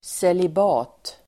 Ladda ner uttalet
Uttal: [selib'a:t]